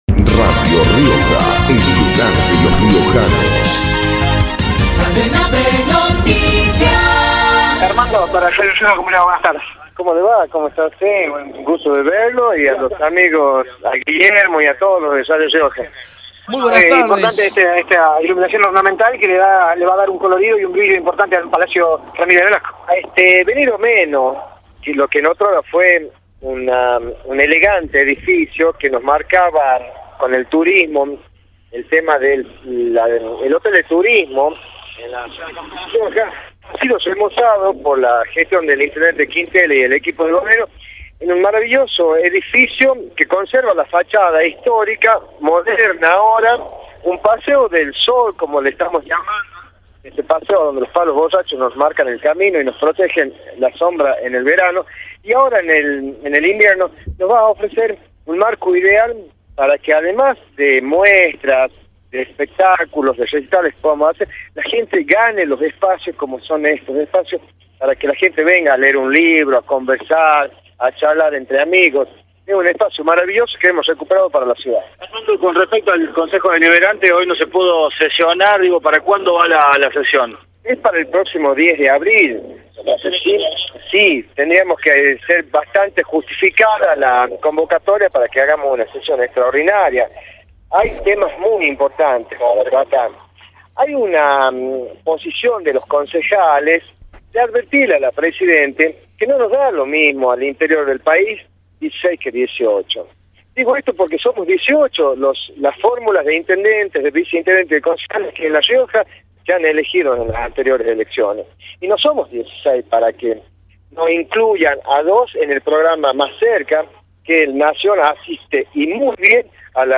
Armando Molina, viceintendente, por Radio Rioja